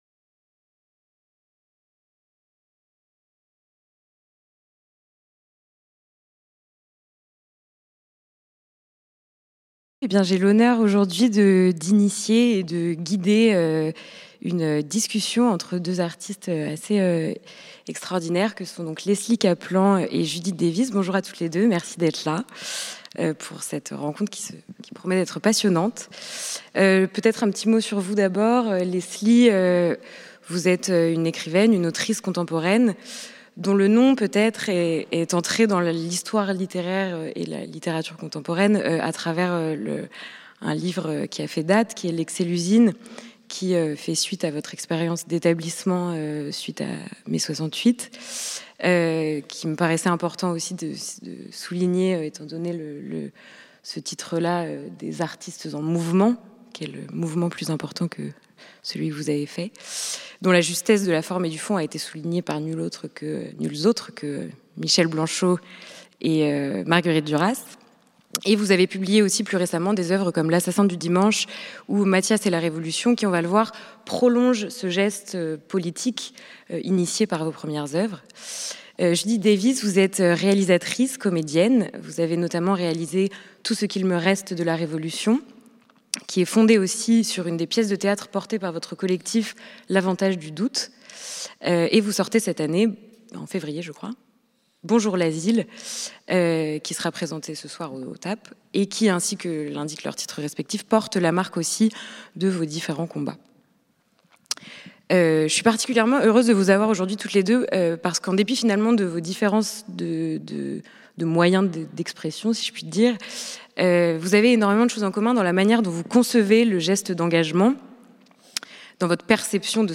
intellectuels et artistes en mouvement Table ronde avec Leslie Kaplan et Judith Davis